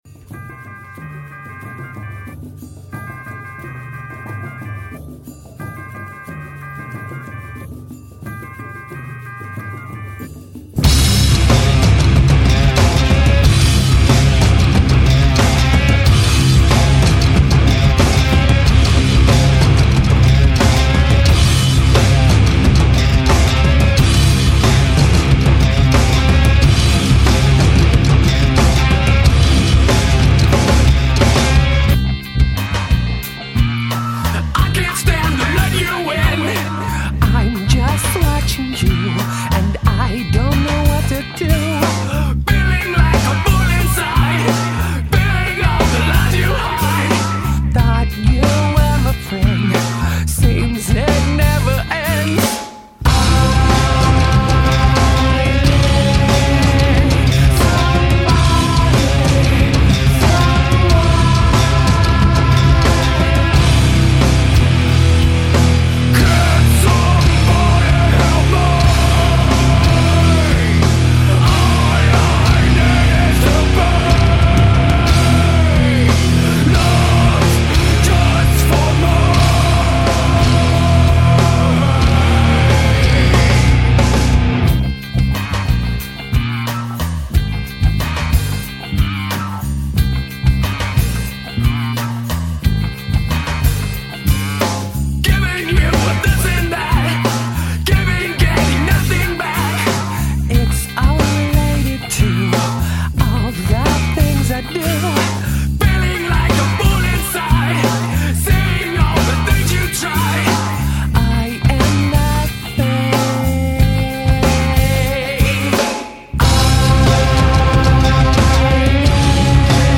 Жанр: Nu-Metal